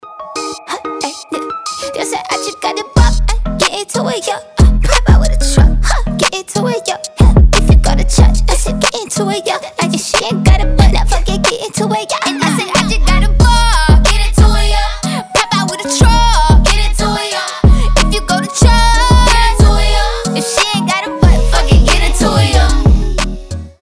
Хип-хоп
забавные
Забавный женский хип-хоп